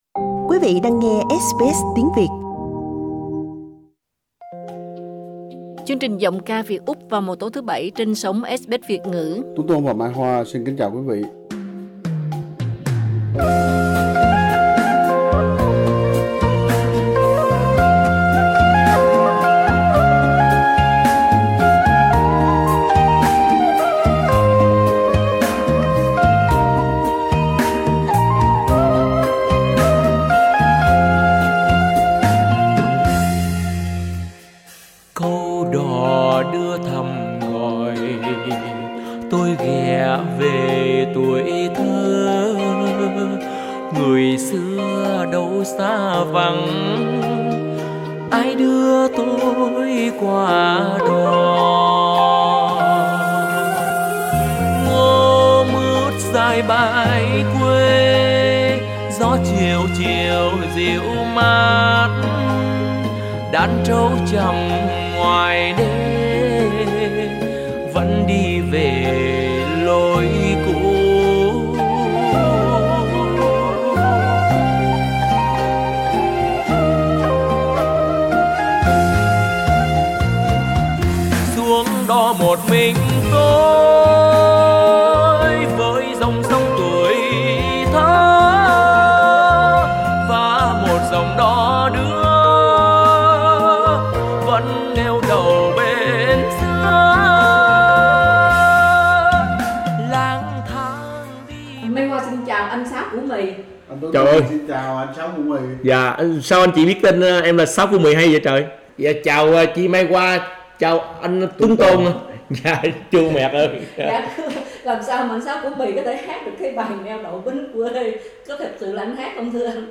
Hát tân nhạc hay mà hát vọng cổ cũng 'bá cháy'.